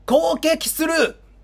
Sound effect
发令员和敌人被吸引的音效已经裁剪完成
罕见语音4.wav